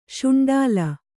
♪ śuṇḍāla